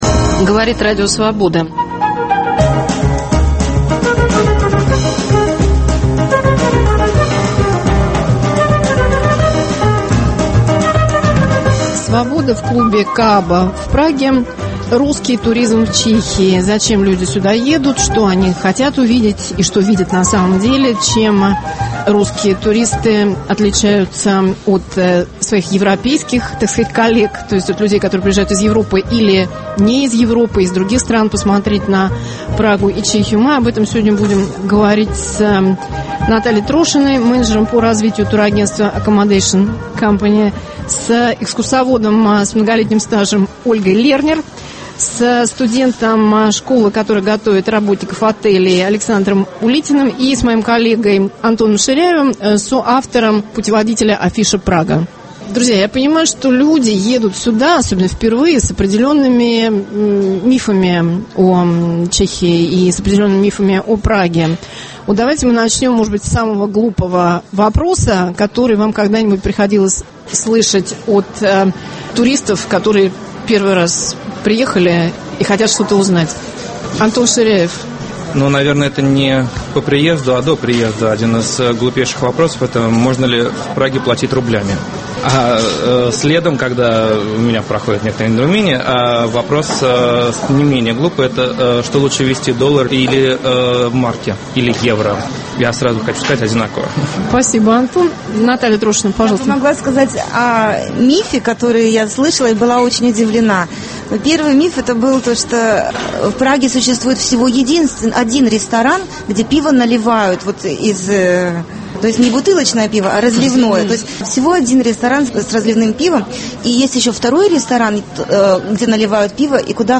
Свобода в клубе Кааба, Прага. Русские туристы в Чехии.